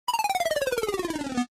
minesweeper_winfail.ogg